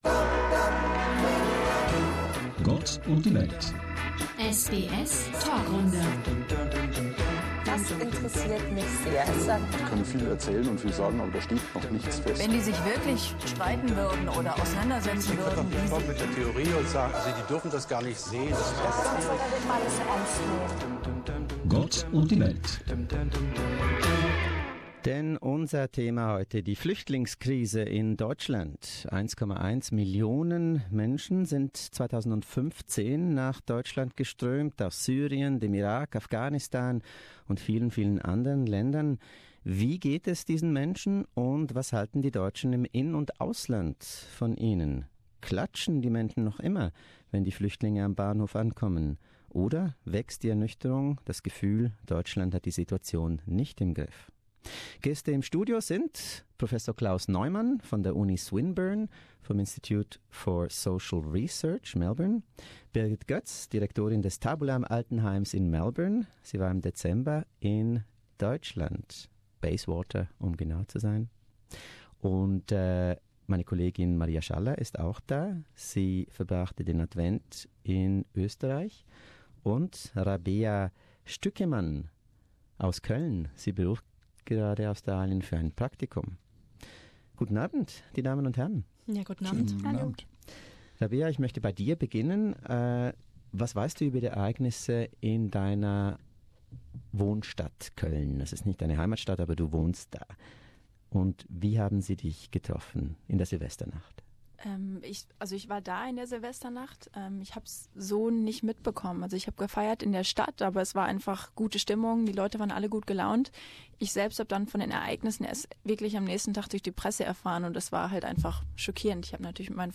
SBS panel discussion: Refugee crisis in Germany